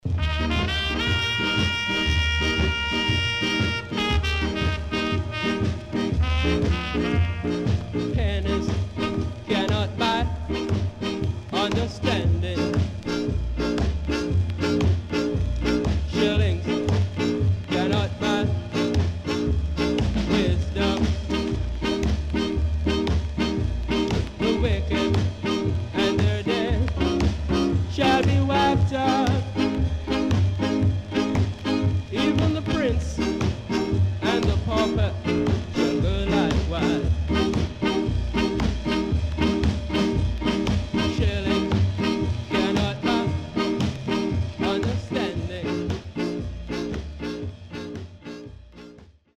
HOME > SKA / ROCKSTEADY  >  SKA  >  EARLY 60’s
SIDE A:所々チリノイズがあり、少しプチノイズ入ります。